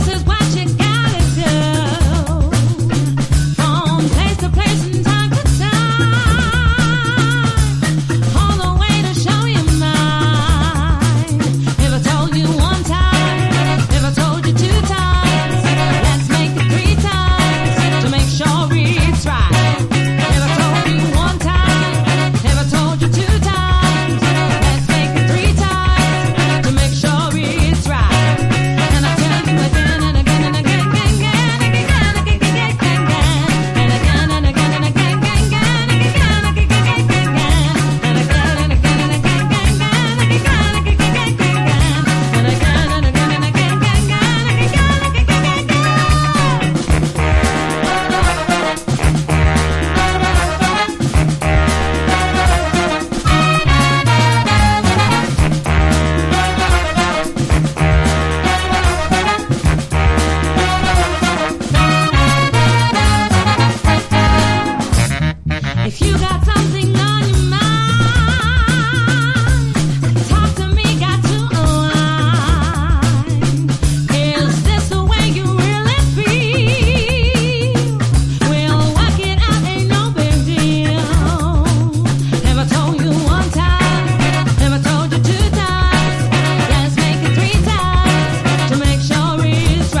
FUNK / DEEP FUNK